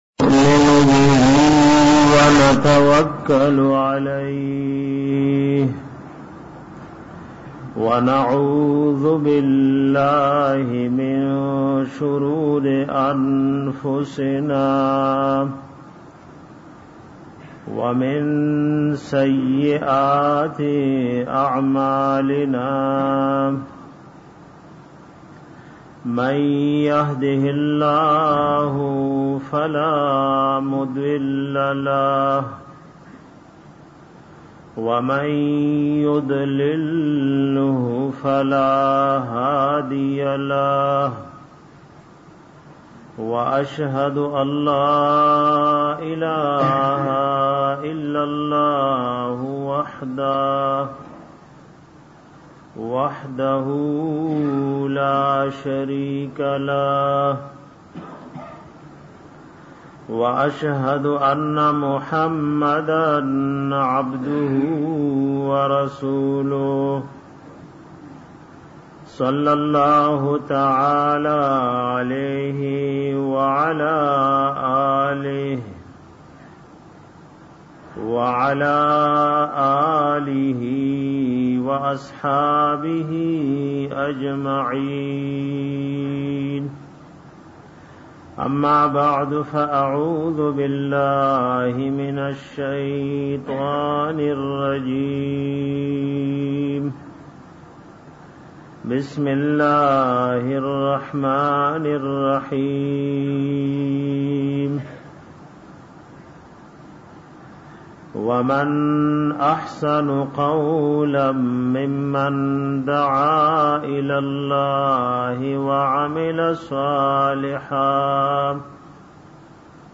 bayan pa bara da fazilat da azan